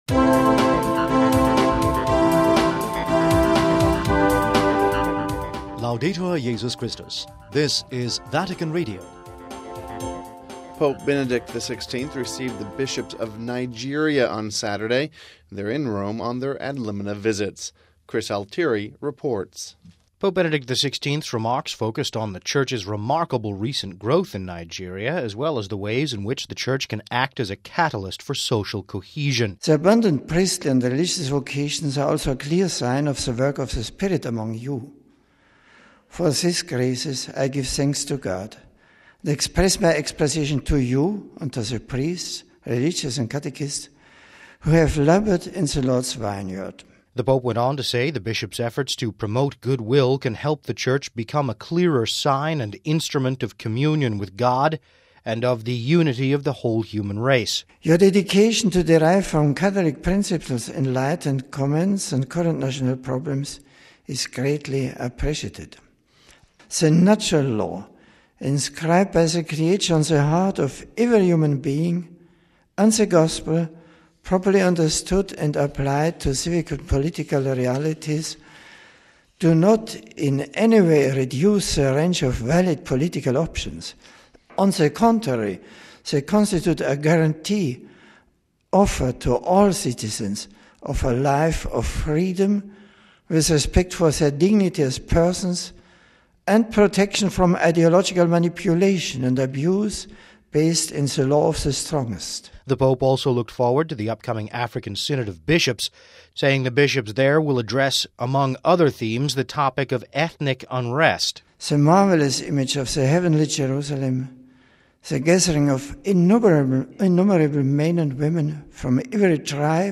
reports…